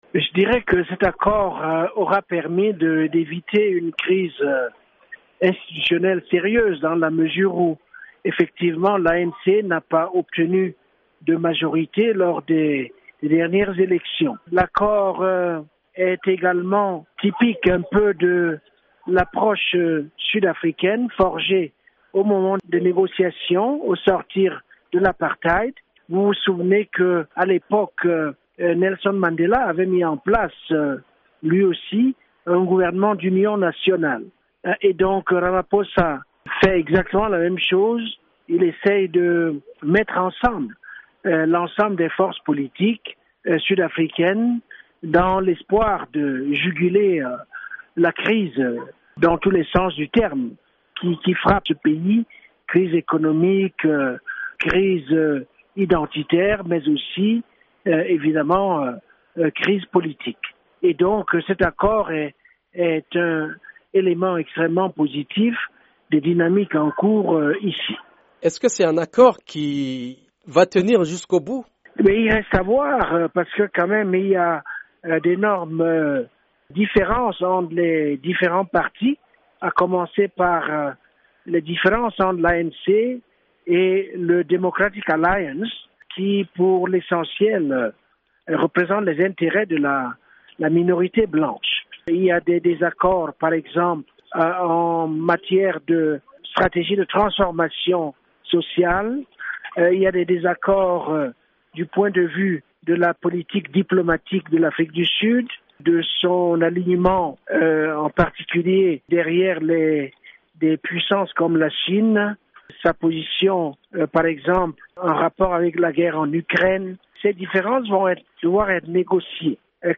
l'historien Achille Mbembe.